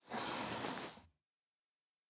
01_院长房间_扶正相框.ogg